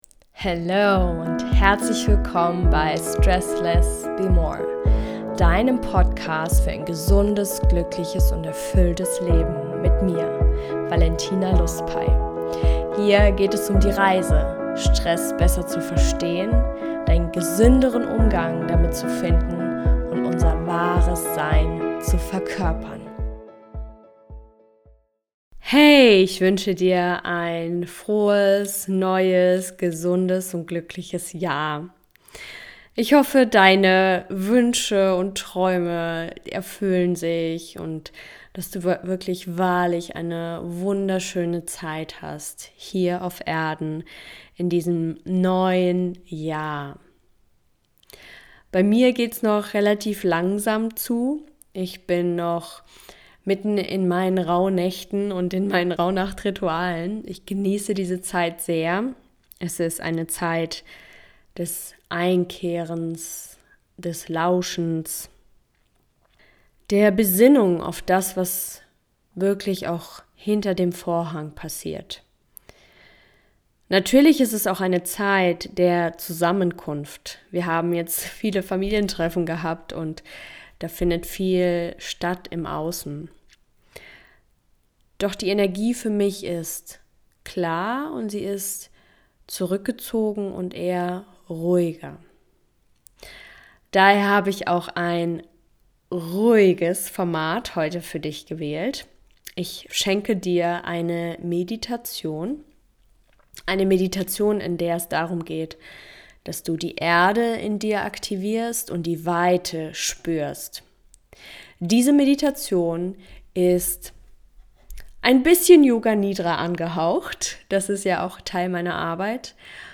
Diese geführte Morgenmeditation begleitet dich dabei, dich tief zu erden, deine innere Stärke zu spüren und voller Leichtigkeit in den Tag zu starten.